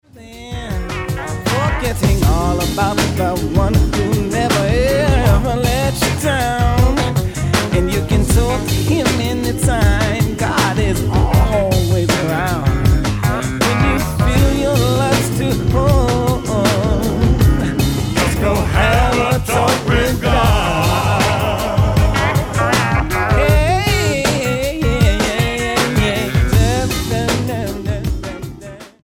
Gospel
quartet